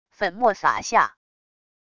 粉末洒下wav音频